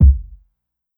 Medicated Kick 1.wav